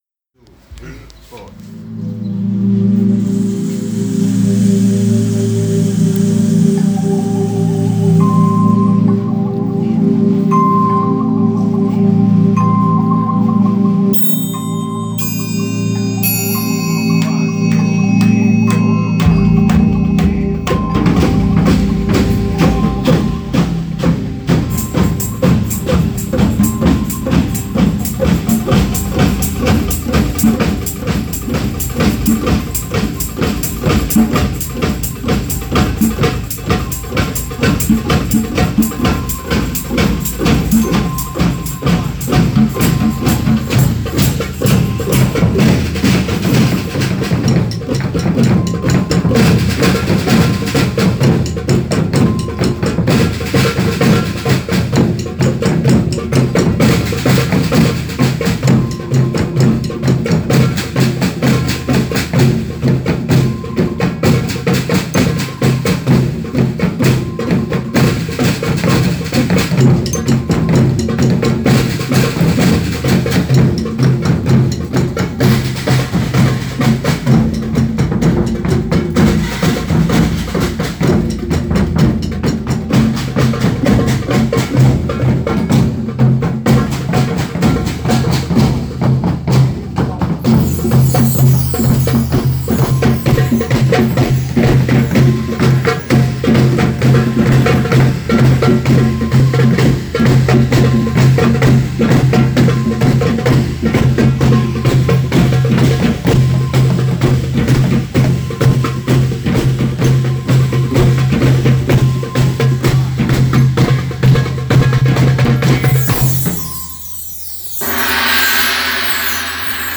Caritas Prison Inmates Programme.
Malta Tama  Tabar Association (MTTA) and Caritas Prison Inmates Programme (PIP) teamed up to plan a percussion performance with the aim to instill hope and empowerment.
The main challenge was to integrate a variety of percussion instruments and adopt a flow of rhythm expressed with different styles of instruments and beats.